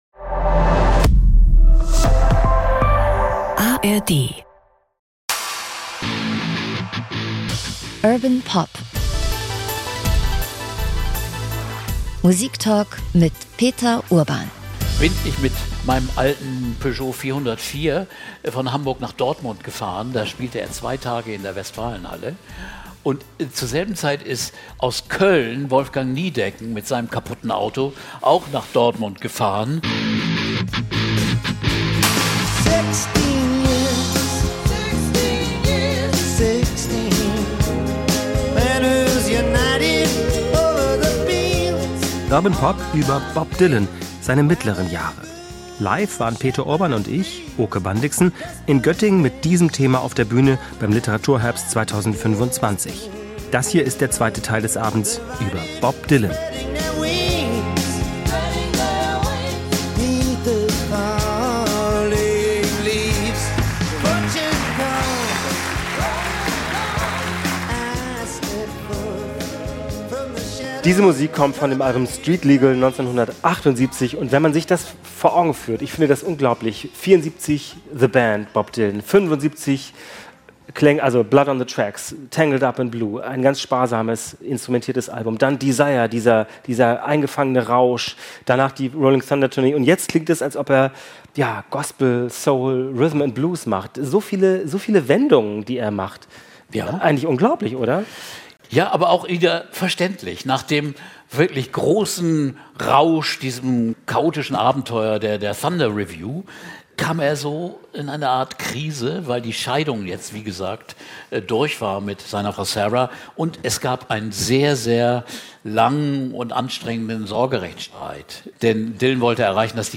Bob Dylan - die späten Jahre (1) ~ Urban Pop - Musiktalk mit Peter Urban Podcast